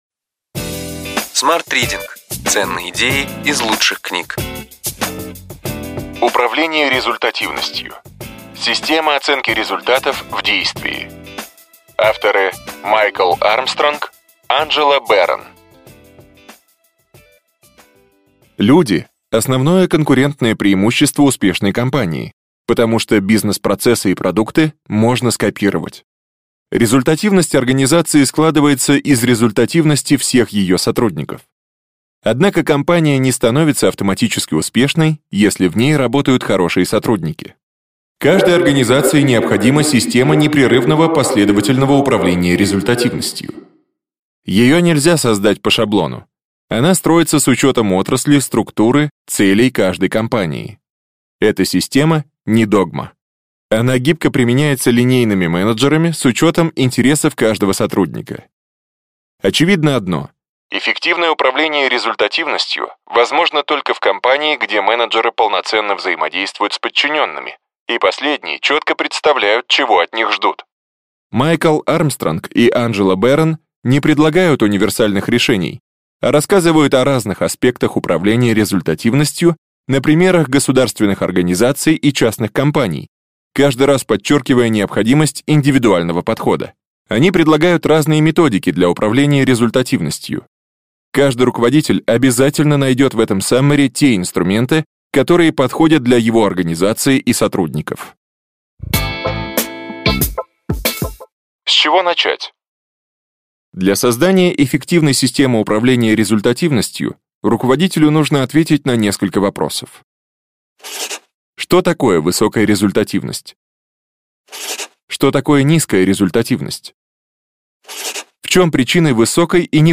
Аудиокнига Ключевые идеи книги: Управление результативностью. Система оценки результатов в действии.